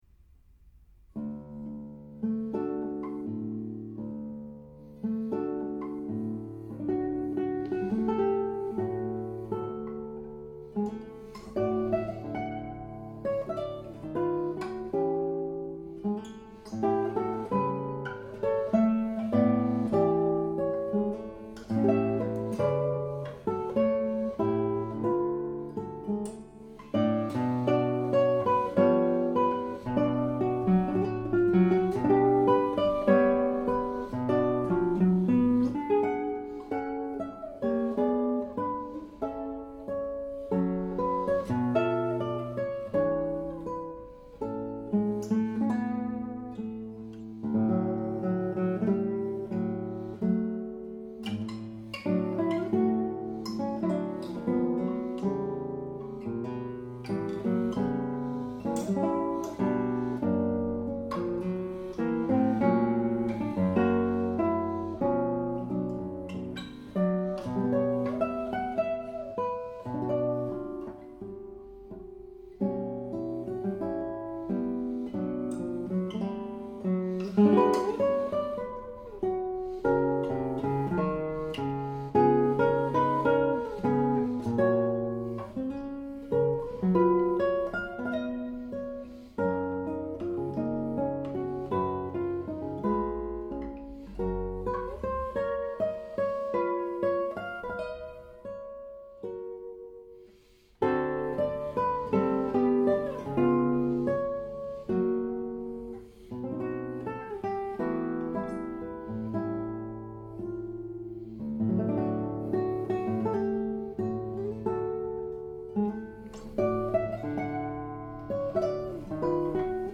Spanish & South American Guitar Music